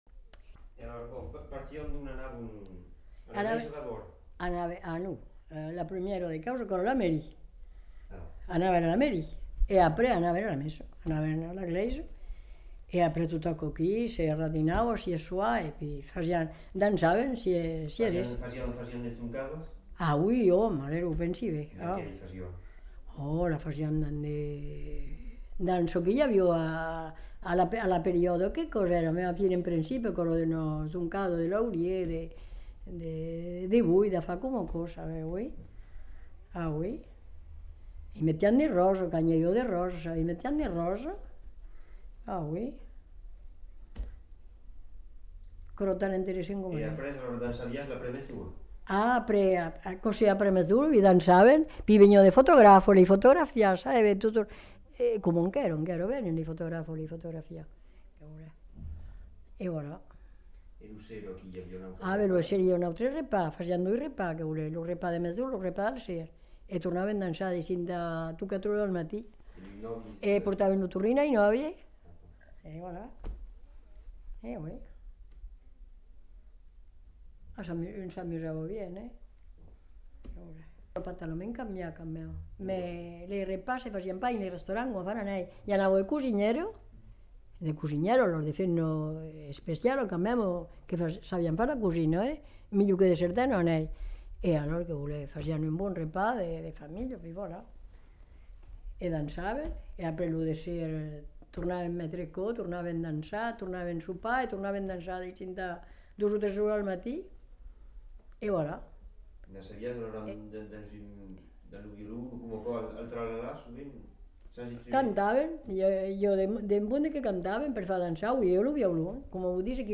Aire culturelle : Haut-Agenais
Lieu : Cancon
Genre : témoignage thématique